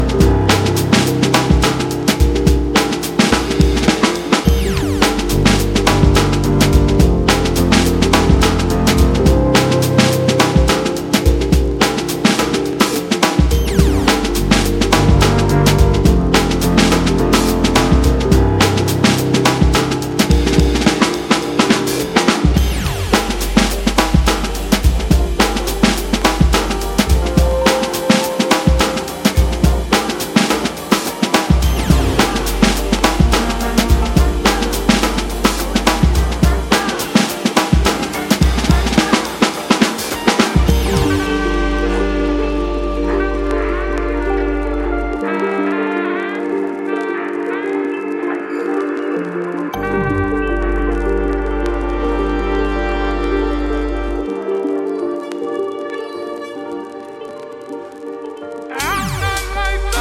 ジャンル(スタイル) ELECTRONICA / DANCE / NU JAZZ / FUNK / SOUL